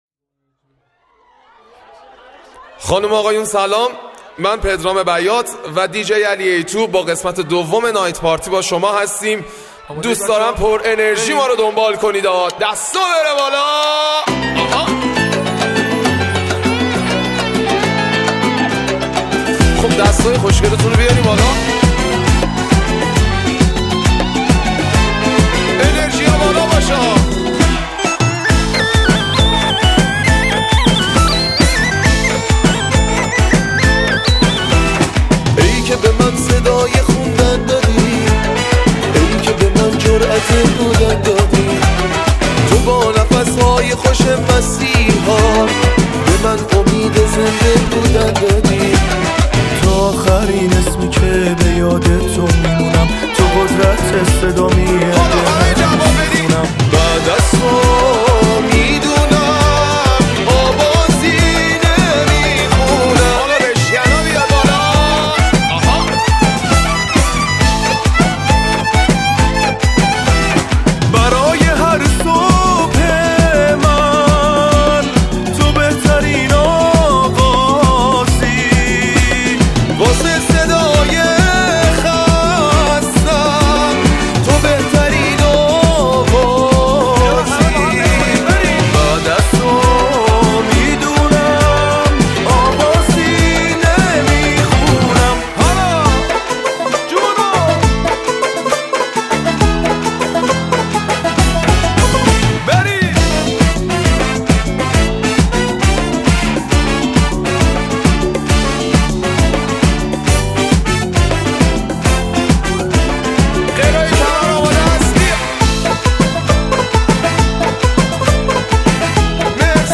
ریمیکس شاد جدید
ریمیکس شاد رقص و دنس
ریمیکس شاد مخصوص عروسی و رقص